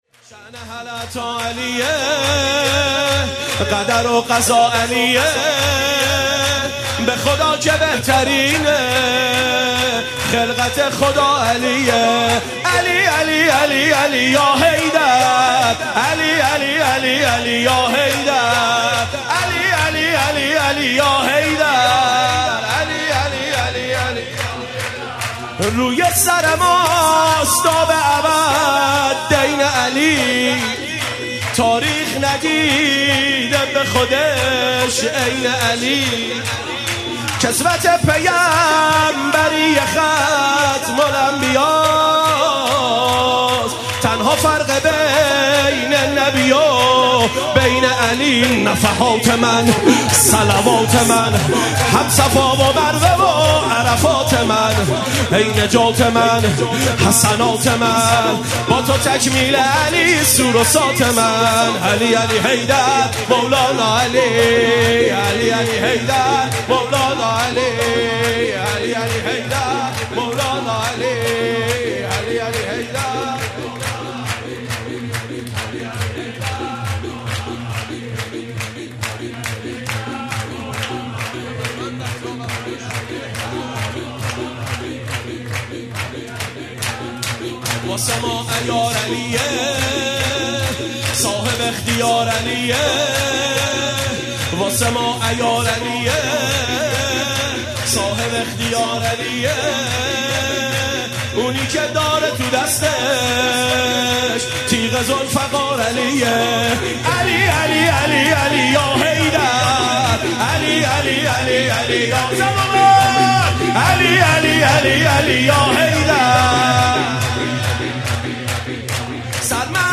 سرود
شب مبعث